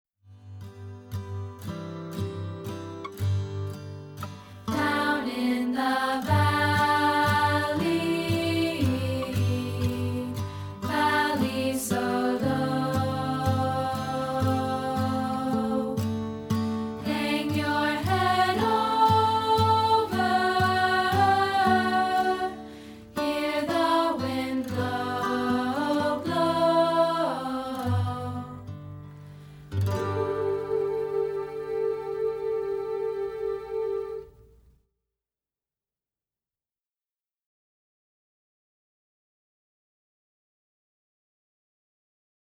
To help teach part 2 of our arrangement of this folk tune